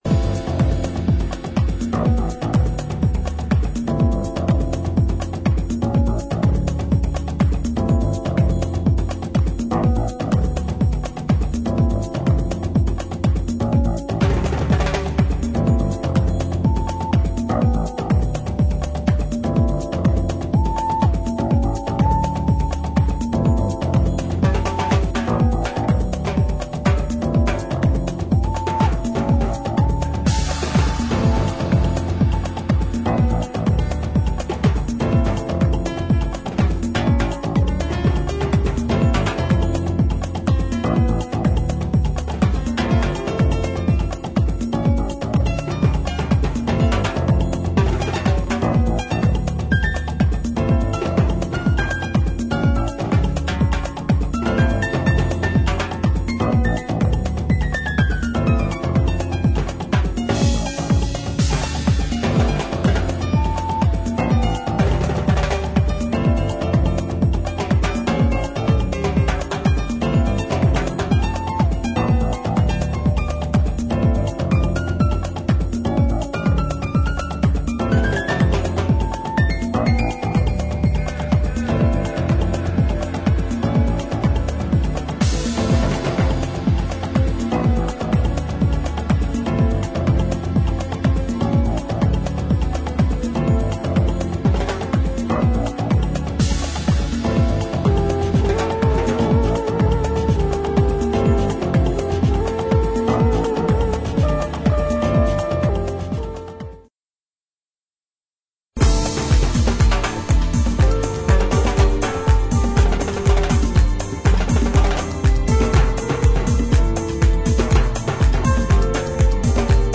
今作も最高としか言えないディープ・ハウス傑作ダブル・サイダー！！